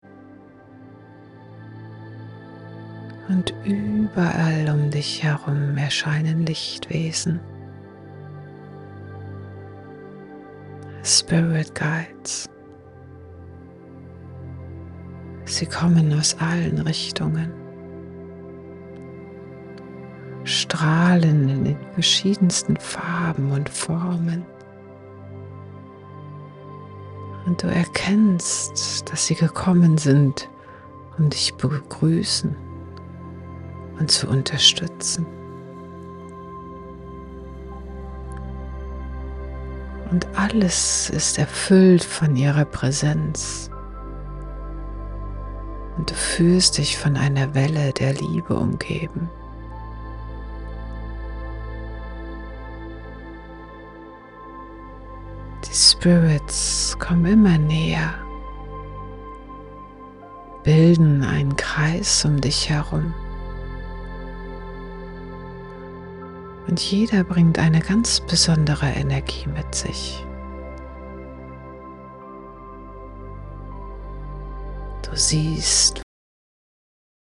In der Soulful Awakening Membership erwarten dich drei transformative Meditationen, die dich auf deiner Reise zu innerer Klarheit und spirituellem Wachstum begleiten